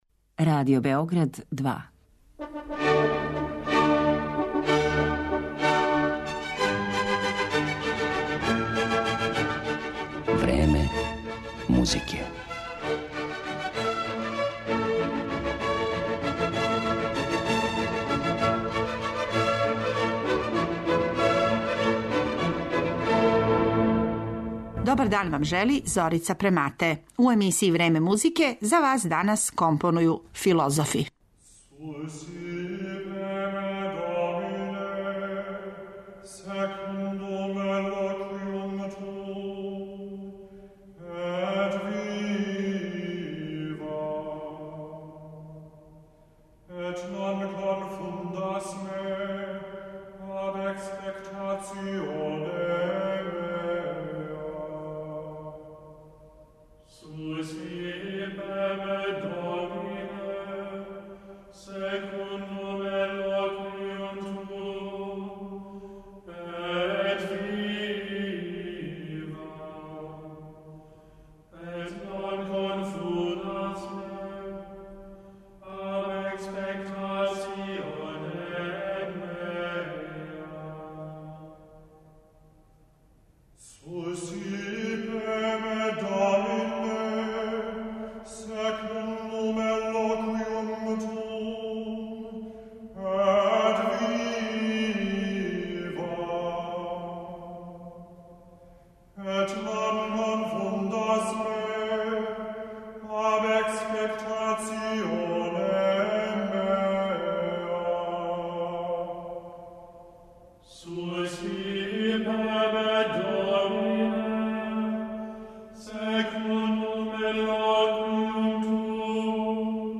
Осим података о њиховом теоријско-филозофском и стваралачком бављењу музиком емитоваћемо и композиције из пера Абелара, Жан-Жака Русоа, Фрудриха Ничеа и Теодора Адорна.